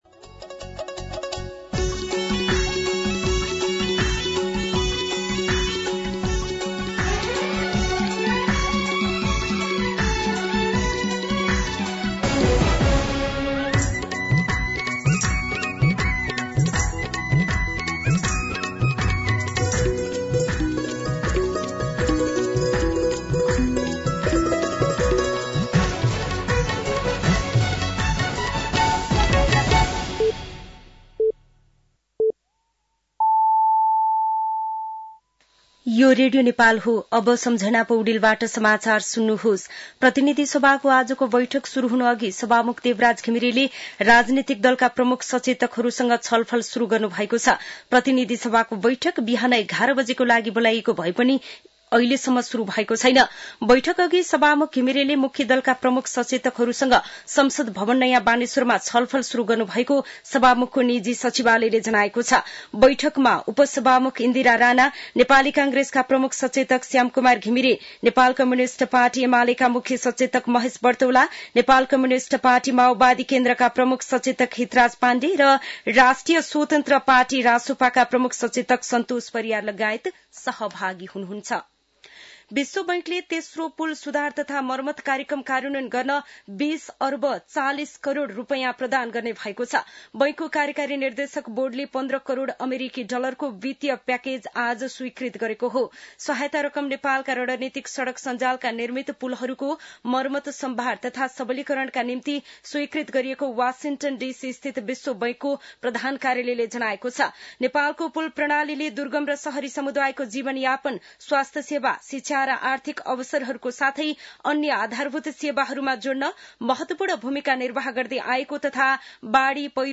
मध्यान्ह १२ बजेको नेपाली समाचार : १७ चैत , २०८१